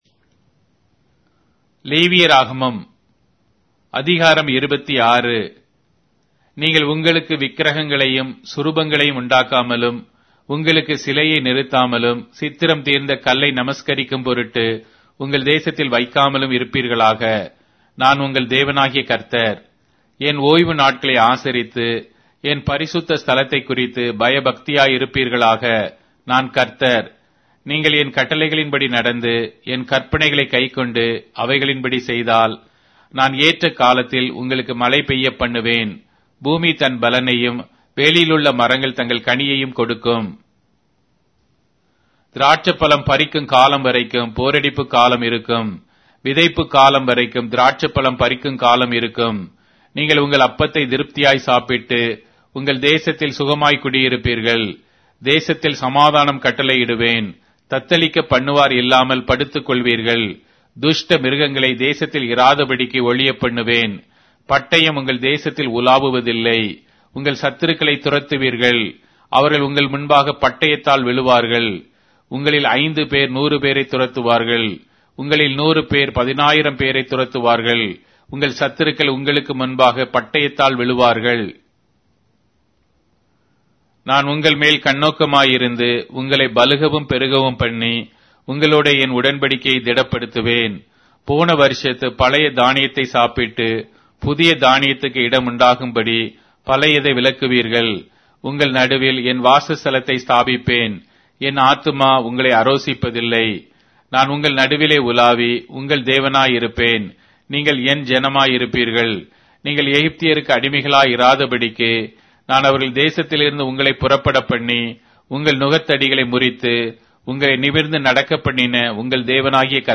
Tamil Audio Bible - Leviticus 2 in Orv bible version